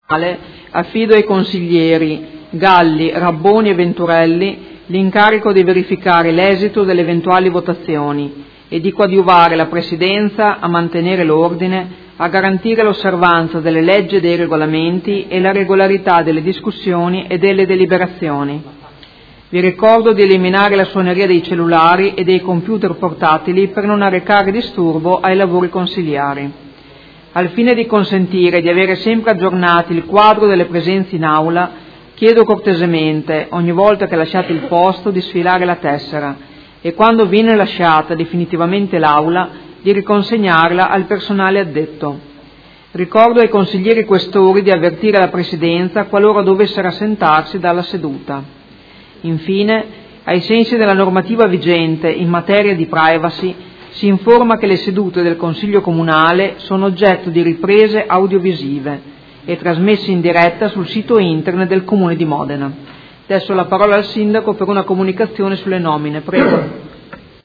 Seduta del 11/05/2015 Apre i lavori del Consiglio Comunale
Presidentessa